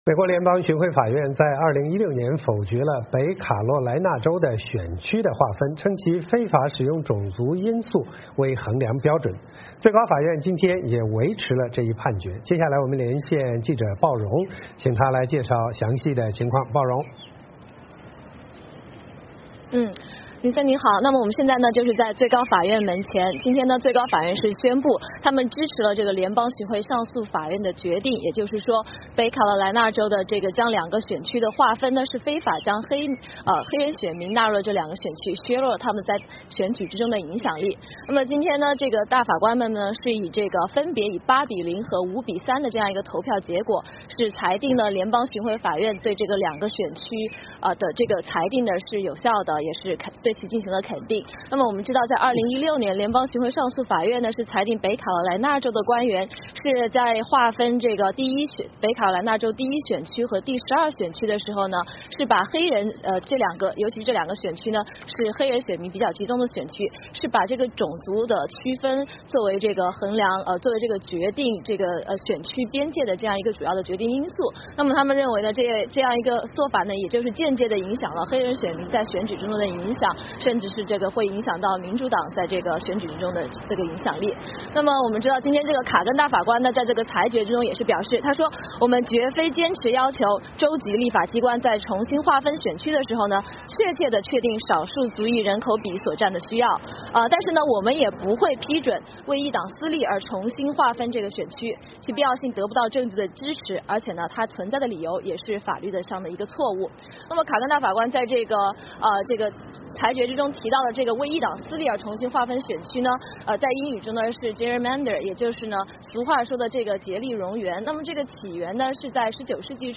VOA连线: 最高法院裁决北卡罗来纳州非法划分国会选区